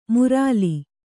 ♪ murāli